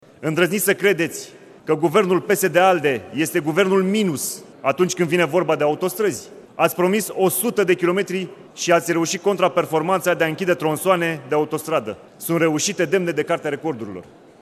Până atunci, deputatul PNL Dan Vâlceanu a dat luni citire criticilor aduse Cabinetului Tudose, în absența miniștrilor.